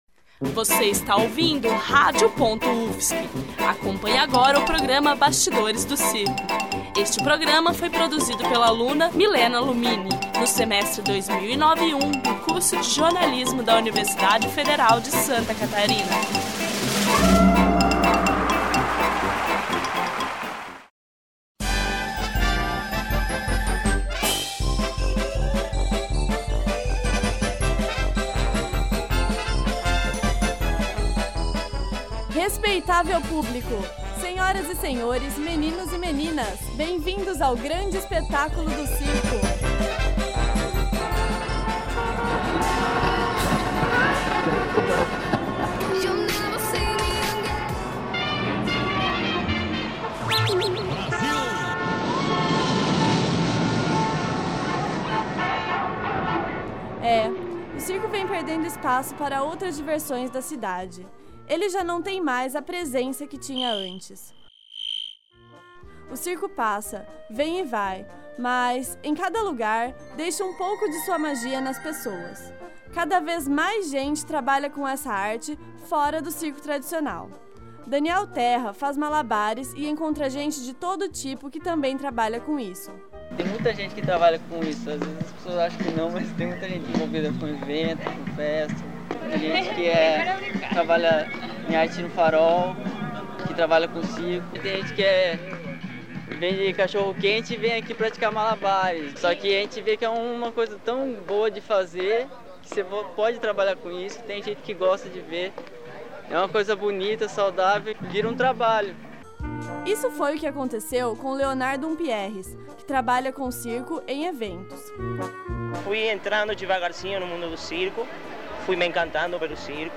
Abstract: Temático sobre os bastidores do circo moderno. O programa fala como o circo sobrevive nas cidades e traz depoimentos de artistas circenses que contam sobre a vida nômade e a profissão.